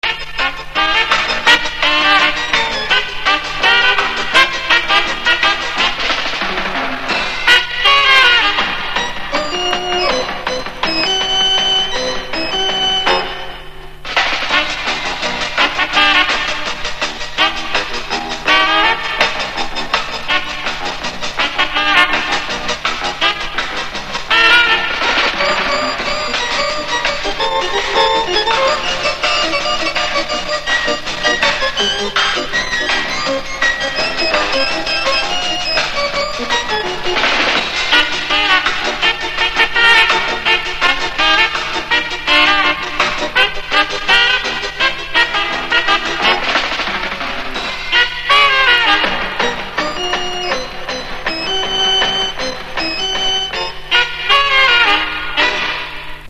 Танцевальная мелодия 60-х. Подскажите что это за танец
Чем то на твист похоже, но не совсем
korotkaya-instrumentalka-(zapis-60-h-godov).mp3